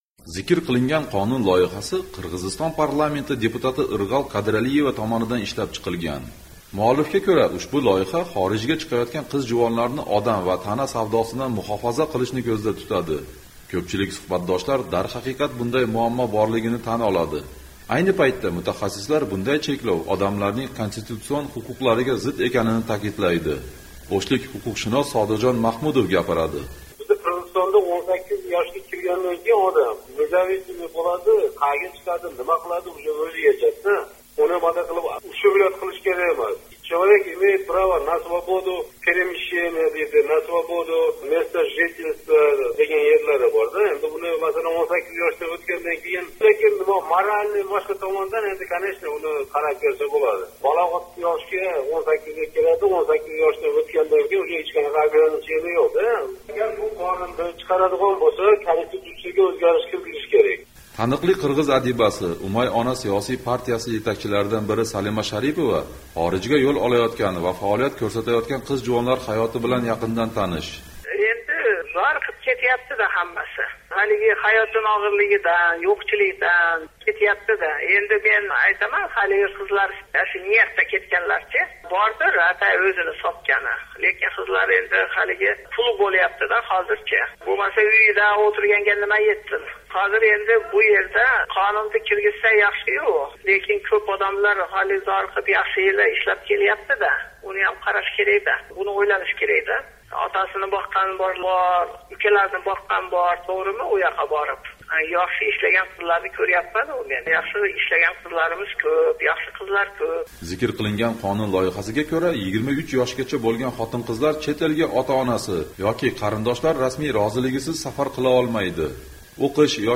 “Amerika Ovozi” mahalliy aholi vakillari, faol ayollar va huquqshunoslar fikrini so’radi.